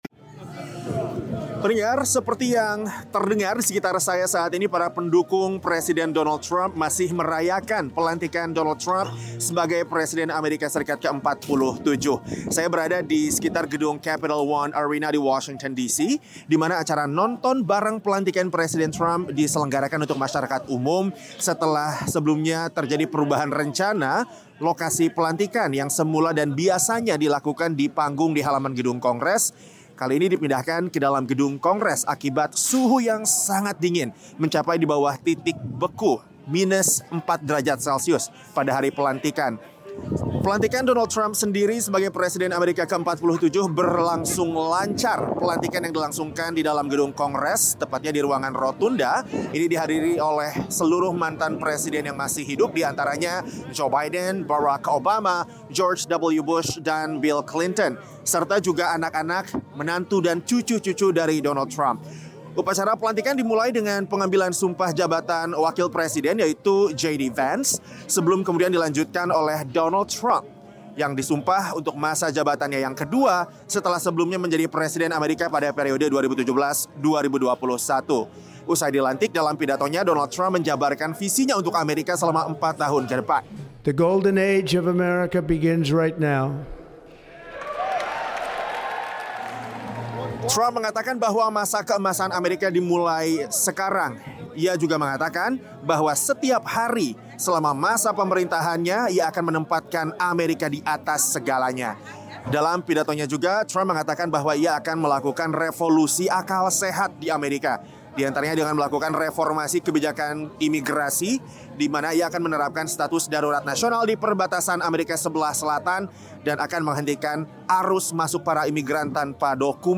Pelantikan Presiden Donald Trump: "Masa Keemasan Amerika Dimulai Sekarang"